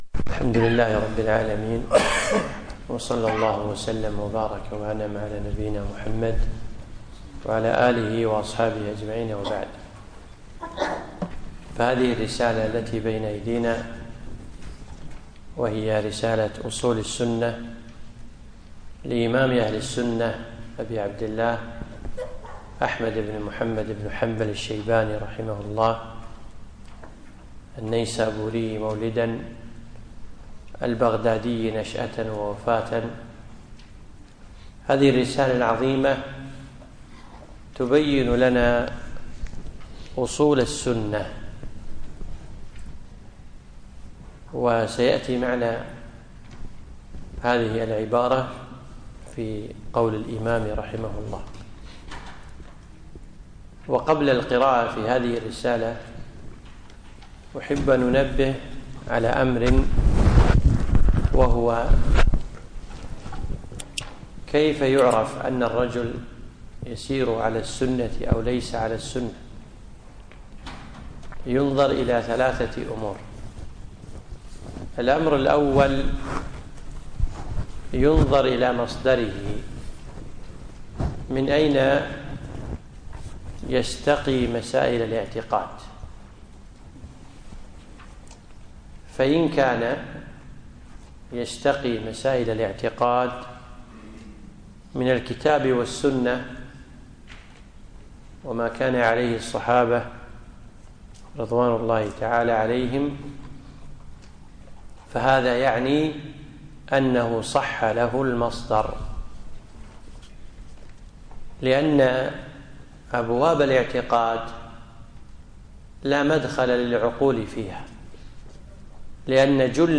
يوم الخميس 29 ذي العقدة 1437هـ الموافق1 9 2016م في مسجد الهاجري الجابرية